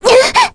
Selene-Vox_Damage_kr_01.wav